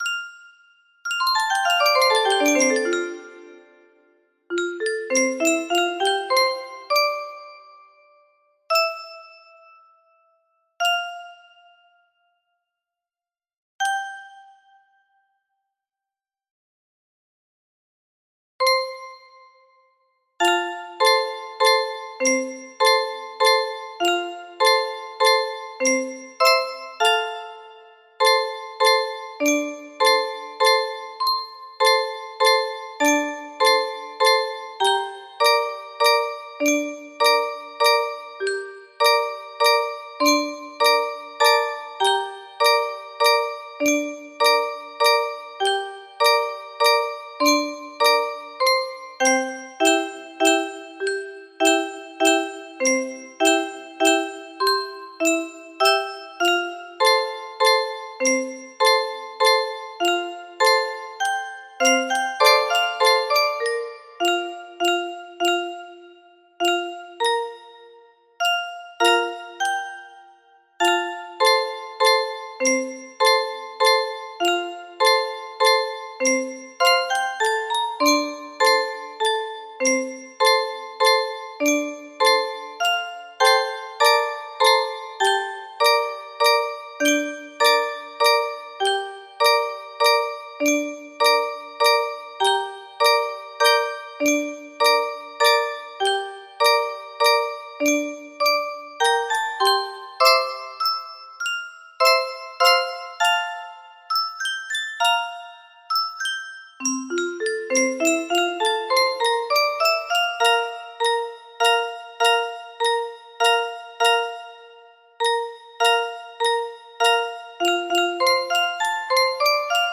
sad piano